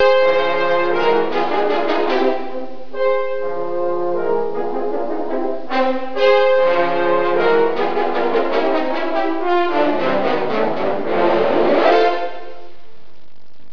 Hier einige Soundfiles, die einen Einblick in die Leistungen bei unserem letzten Klassenabend geben sollen.